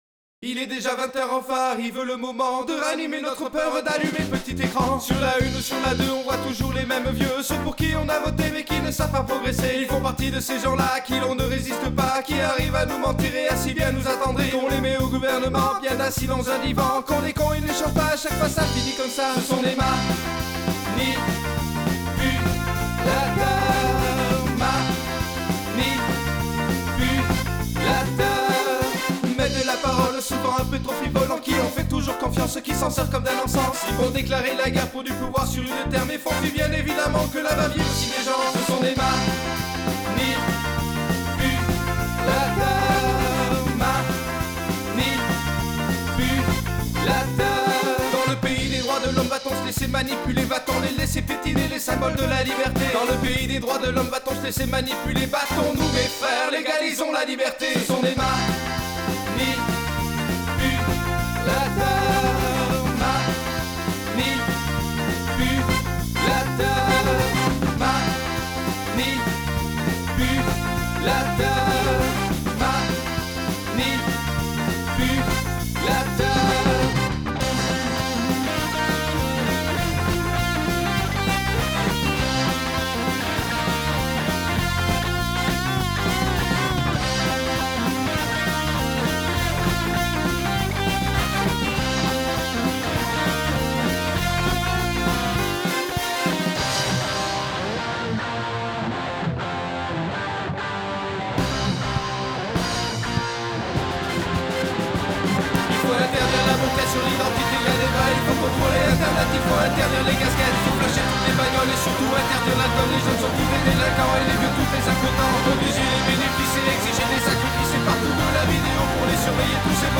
Je travaille sur une maquette et je fais les prises à la maison.
Micro : B2Pro pour les voix et l'ambiance batterie, SM58 pour la grosse caisse et les toms.
Voici la version wav avant master :
Cela sature encore de partout...
dsl mais bon manque de définition, de lisibilité de tous les instruments. Sûrement dû à la compression des instrus mal gérée...
Par exemple, la basse n'a que le bas du spectre...c'est bizarre
Première chose : il y a un gros "clac"à 2'10" (je ne sais pas ce que c'est).
Le fait que la voix soit souvent doublée empêche de bien saisir le texte (ce qui est dommage).
Et le son global me parait "acide" (je ne vois pas d'autre terme).